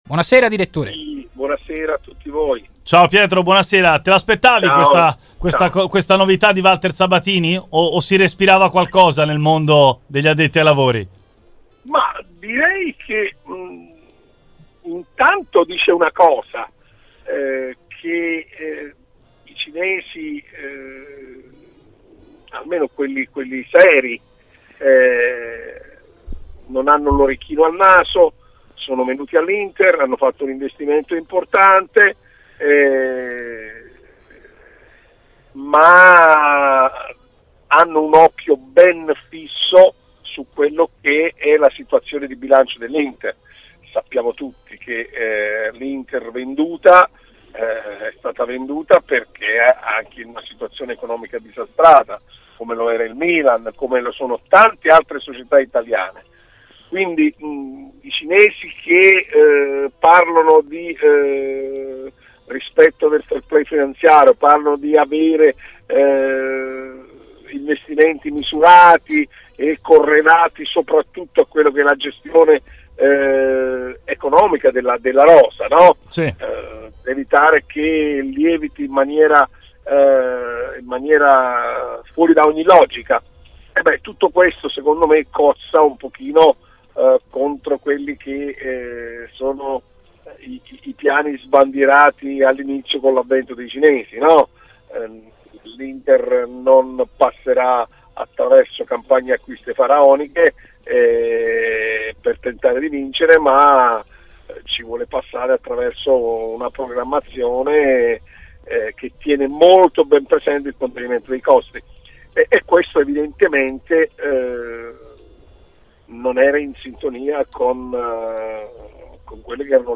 durante il suo intervento nel Live Show di RMC Sport ha analizzato così la notizia della separazione tra Sabatini e il gruppo Suning